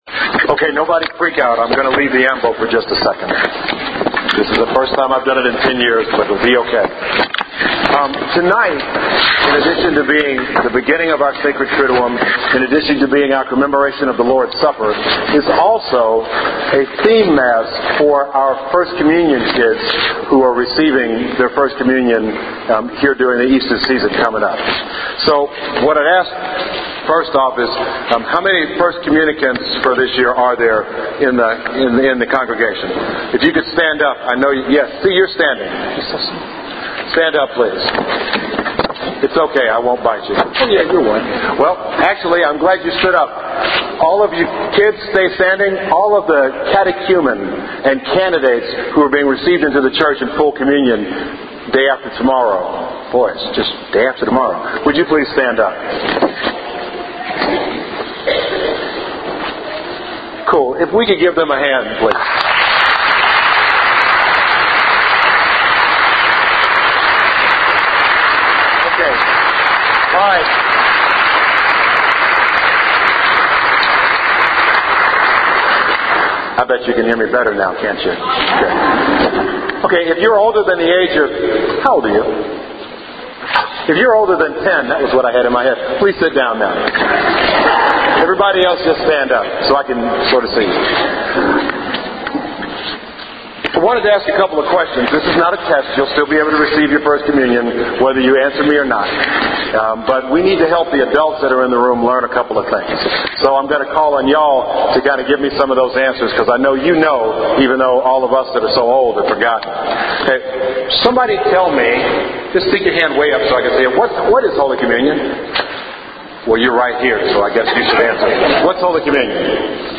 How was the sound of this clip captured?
There is a brief children’s homily before all this (don’t freak out!).